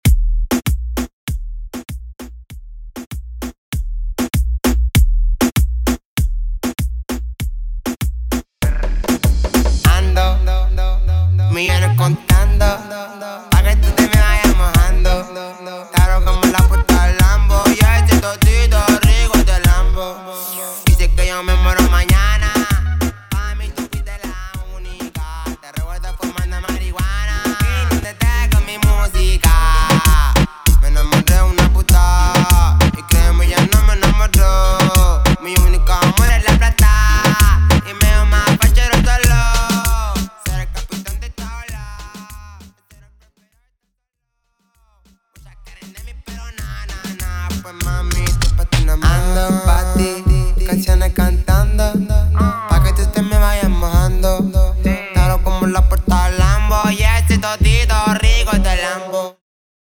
DJ